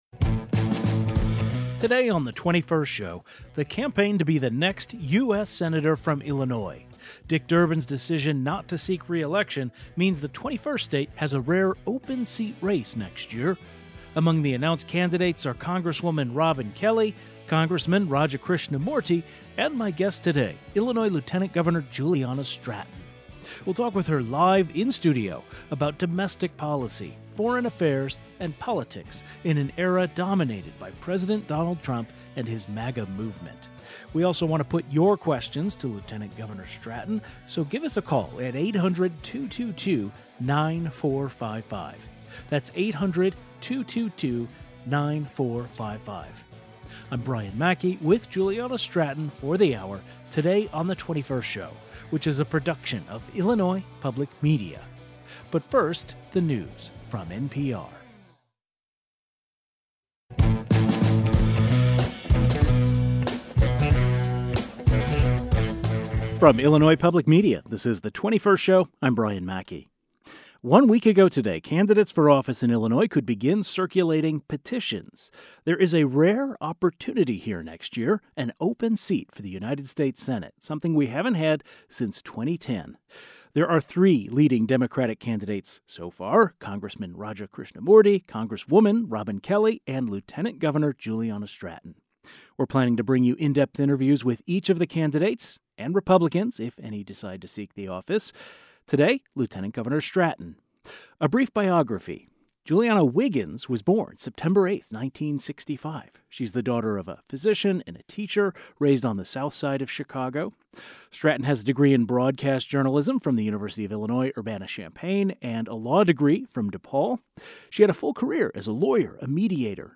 To kick off our series of in-depth interviews with each of the candidates seeking to replace Senator Dick Durbin, Lt. Governor Juliana Stratton joins us live. The 21st Show is Illinois' statewide weekday public radio talk show, connecting Illinois and bringing you the news, culture, and stories that matter to the 21st state.